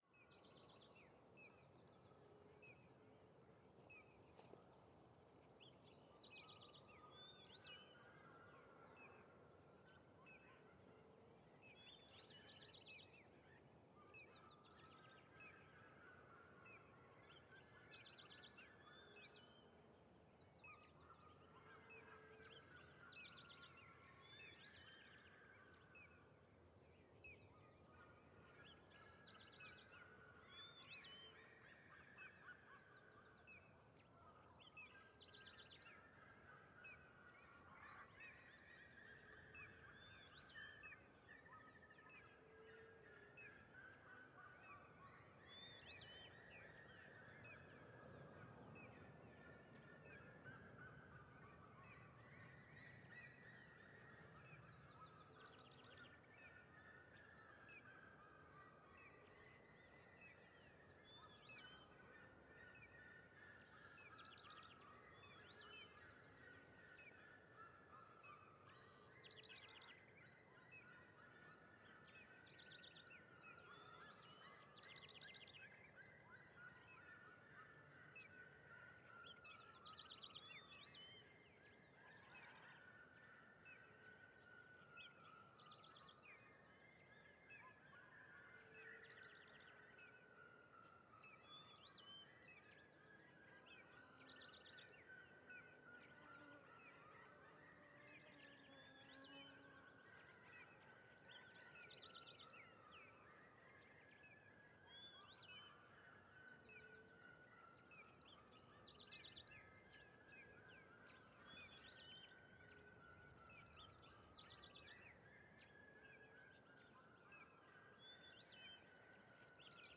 Seriemas Cantando em Vale do Sertao Zen desde o Morrao
Pássaros
Vento suave
Surround 5.1
CSC-05-100-GV - Ambiencia do Amanhecer no Alto do Morrao Trilha Sertao Zen de Alto Paraiso Apontado a Cidade.wav